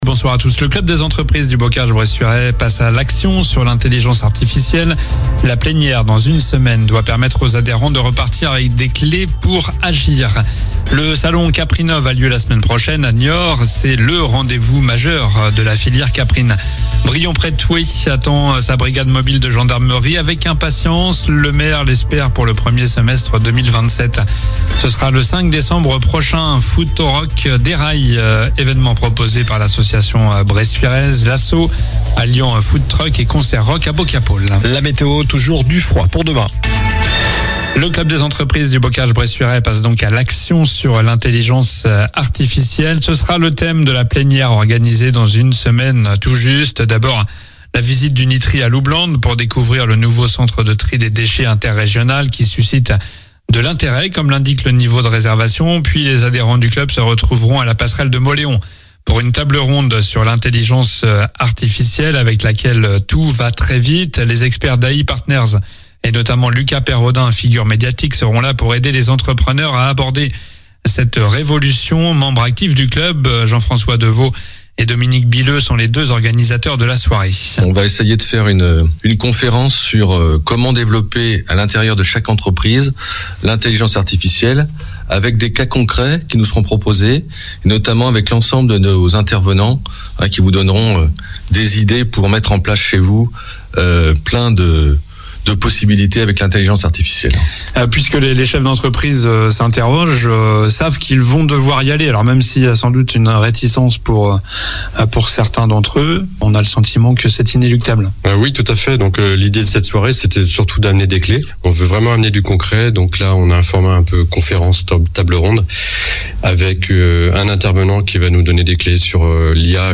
Journal du jeudi 20 novembre (soir)